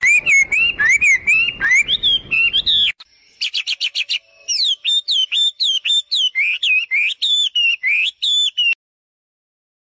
9s嘹亮画眉母鸟鸣叫声 公眉必应